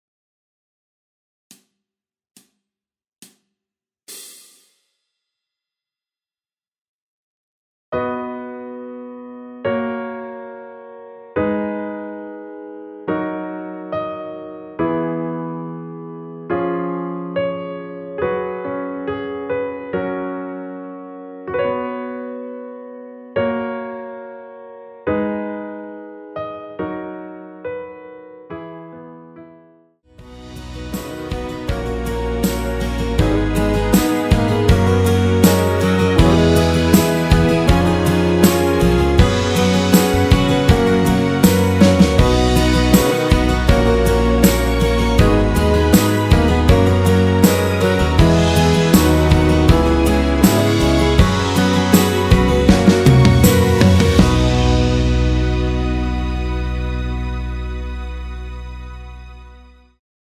노래가 바로 시작하는곡이라 카운트 넣어 놓았으며
그리고 엔딩이 너무 길고 페이드 아웃이라 라랄라 반복 2번으로 하고 엔딩을 만들었습니다.
◈ 곡명 옆 (-1)은 반음 내림, (+1)은 반음 올림 입니다.
앞부분30초, 뒷부분30초씩 편집해서 올려 드리고 있습니다.